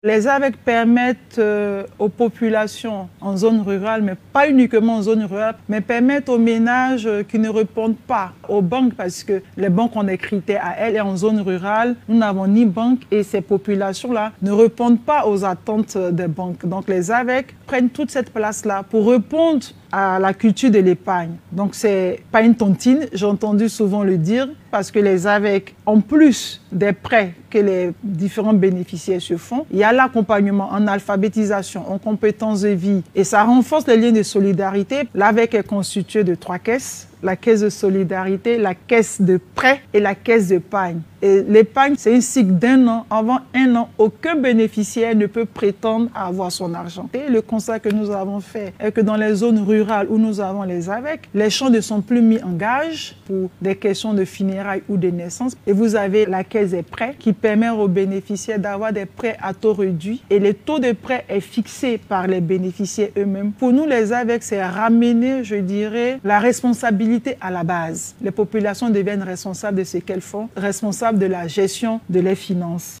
La politique du gouvernement en matière de solidarité et de lutte contre la pauvreté expliquée aux internautes par Myss Belmonde Dogo.
A l’instant, la Ministre de la Solidarité et de la Lutte contre la Pauvreté, Myss Belmonde Dogo, a l’occasion du “Gouv’Talk”, rendez-vous d’échanges en ligne, initié par le Centre d’Information et de Communication gouvernementale (CICG).